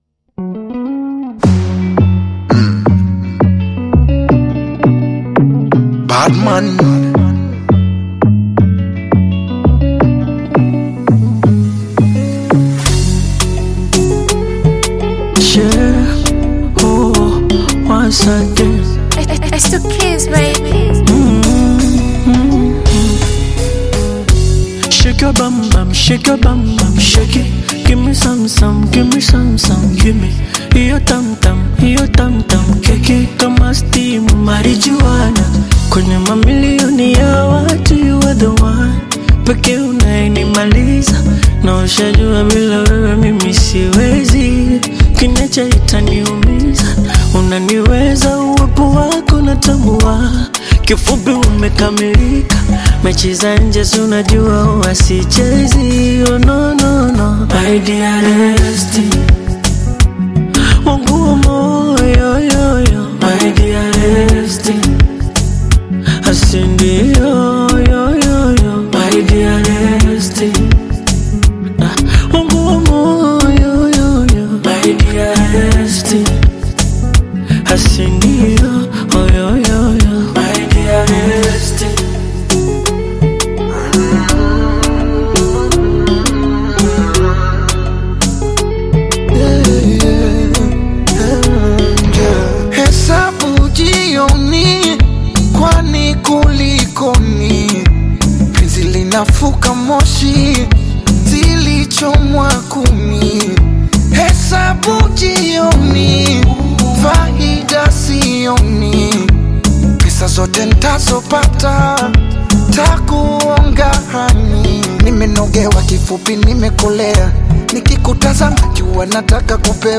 Tanzanian singer